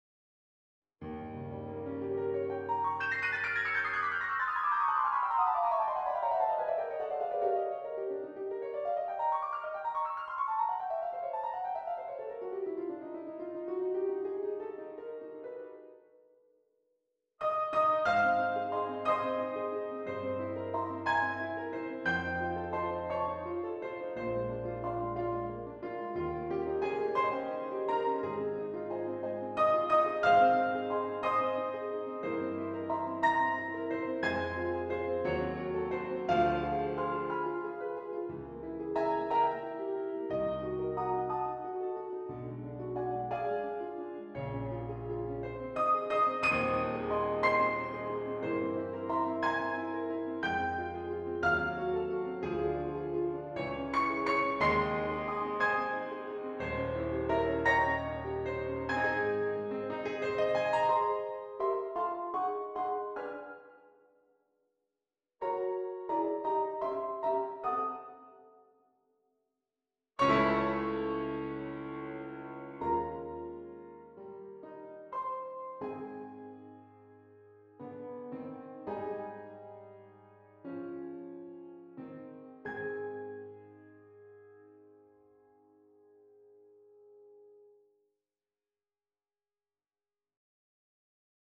‍Happy-Birthday-Greeting-2025 - This version is scored for small orchestra.